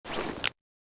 pm_draw.ogg